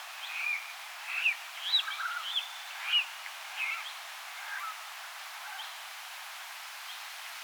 kuuluuko sieltä luhtakanan poikasen
ääntä myöskin
luhtakana_ja_ehka_sen_poikanen_myos.mp3